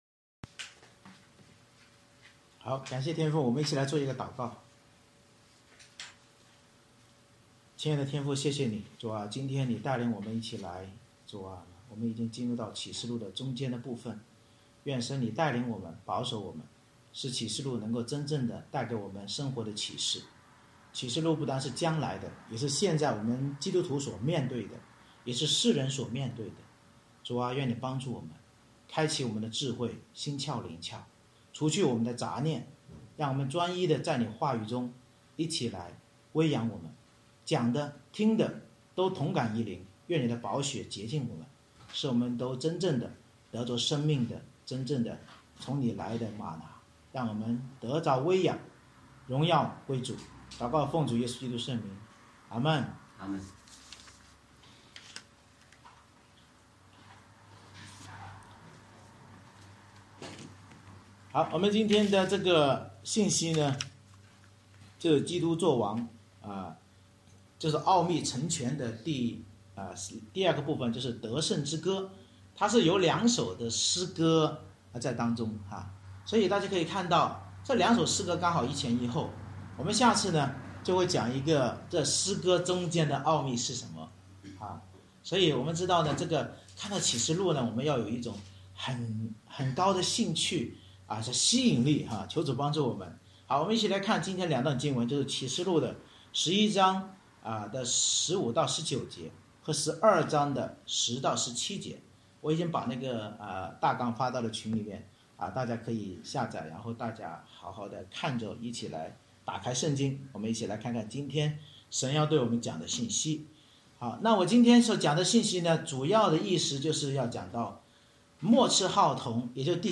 July 4, 2021 奥秘成全——得胜之歌 Series: 《启示录》讲道系列 Passage: 启示录11:15-19，12:10-17 Service Type: 主日崇拜 末次号筒吹响，伴随基督作王的敬拜和圣殿约柜显现后的颂赞，启示我们教会圣徒要依靠羔羊耶稣宝血和真道见证才能胜过穷途末路的魔鬼。